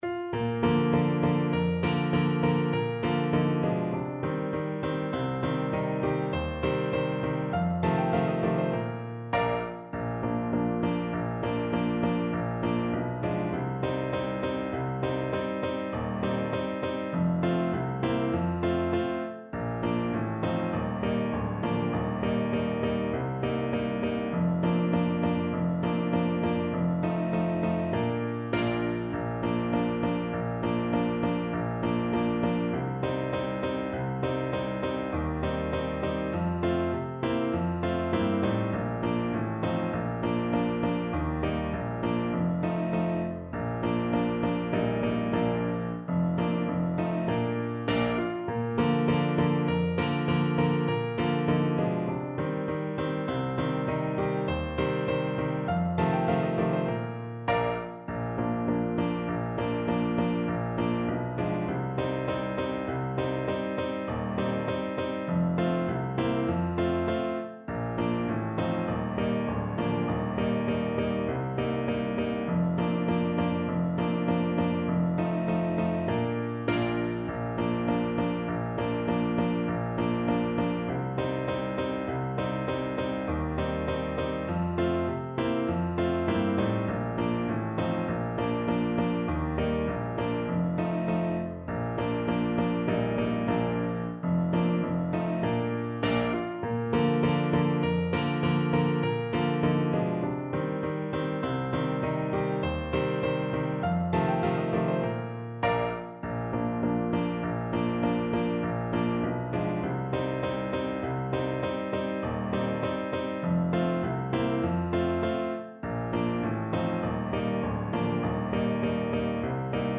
Moderato
2/2 (View more 2/2 Music)